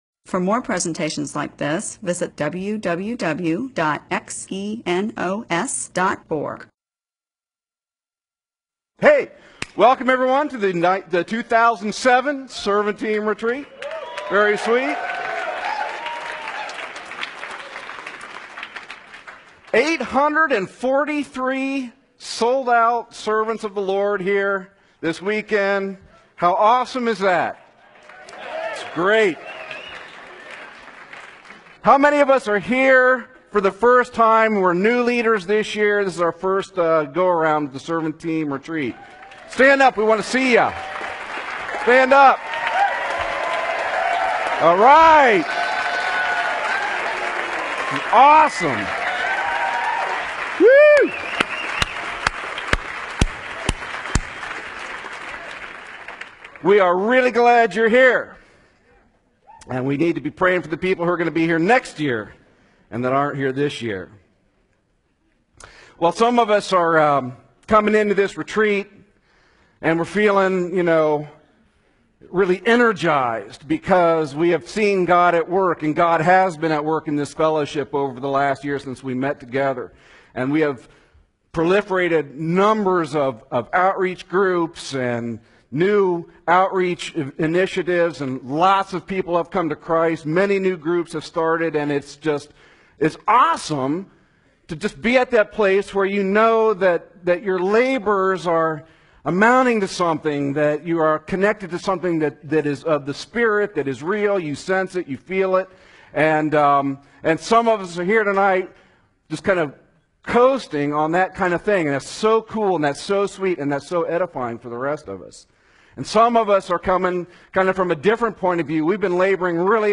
MP4/M4A audio recording of a Bible teaching/sermon/presentation about 2 Timothy 3:14-4:4.